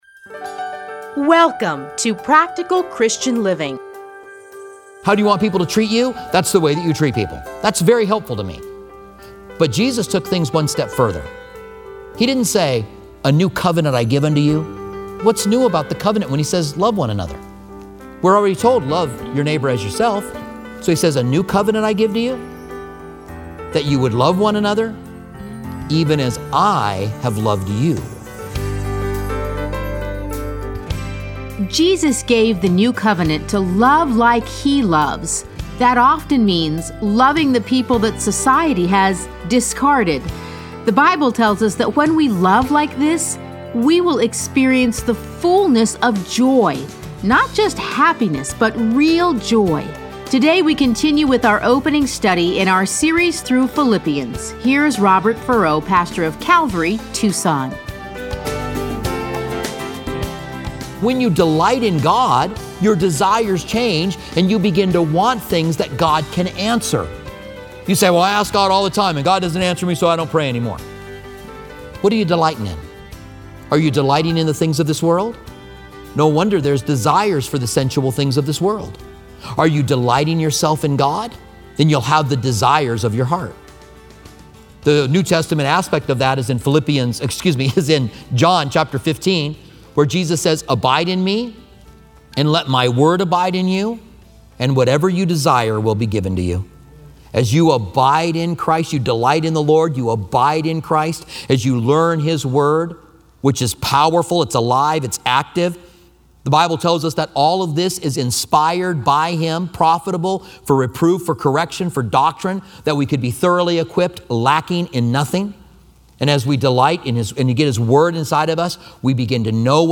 Listen to a teaching from Philippians 1:1-30 Playlists A Study in Philippians Download Audio